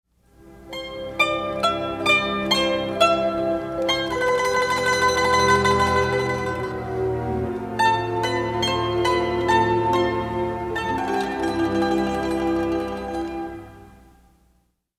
спокойные
инструментальные